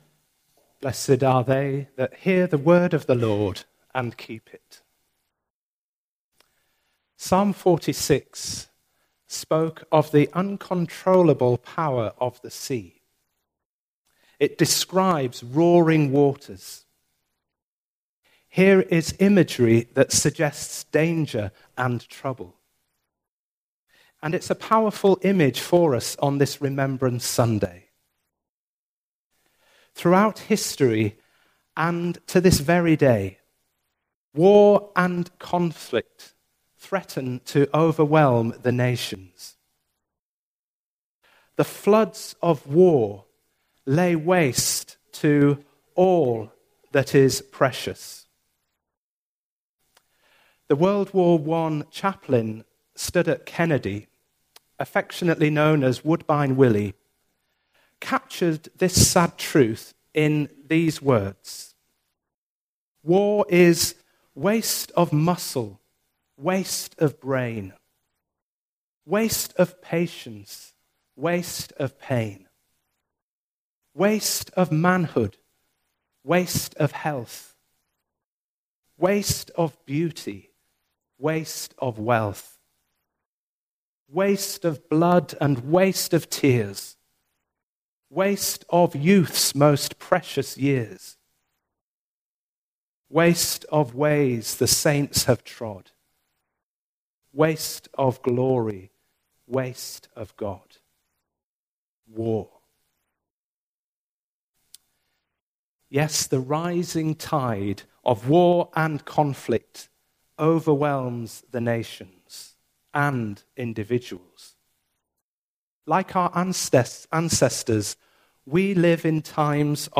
A contemporary service of remembrance with a short video for young people, then a longer one, after the young people leave for their groups and after the message, incorporating the ‘Last Post’, the ‘Silence’ and the poem ‘In Flanders Fields’.